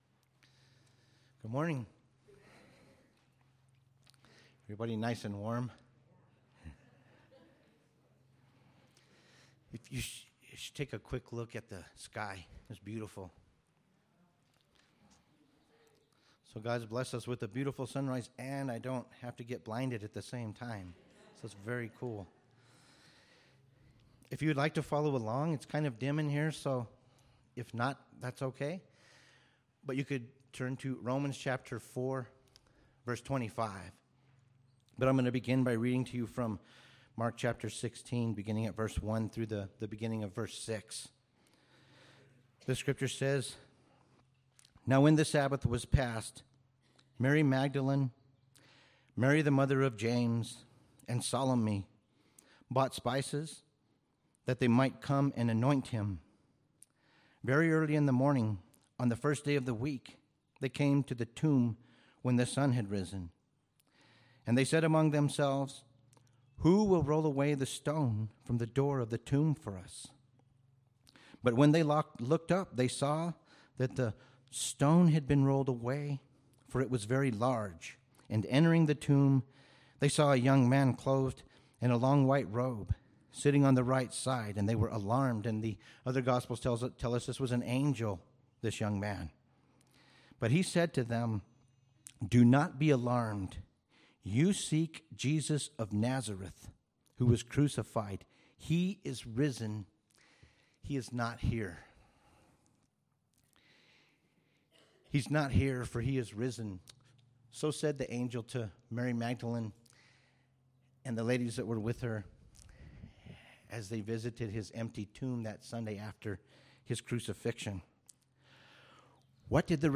Easter Sunrise Service